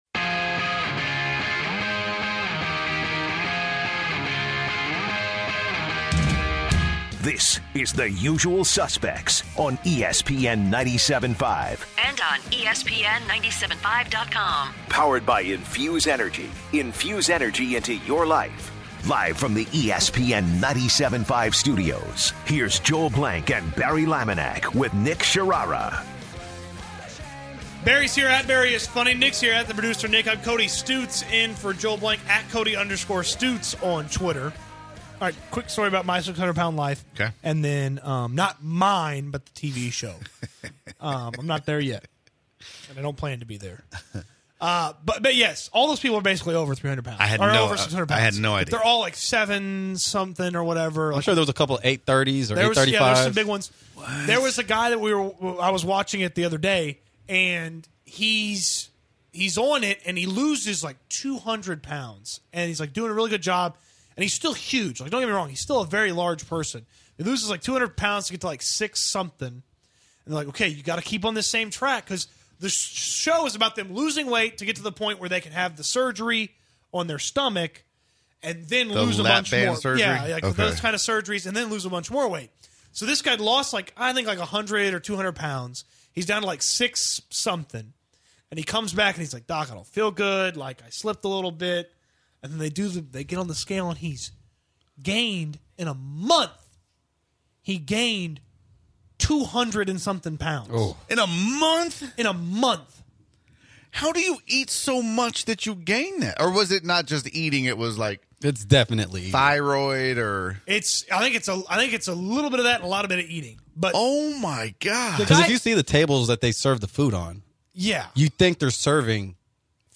The guys start the last hour of the show talking about a man on a weight loss show losing 200 pounds and gaining it back in a month. The guys get into the question would they be able to gain that much weight and they take callers who explain more about gaining weight and weight loss surgery.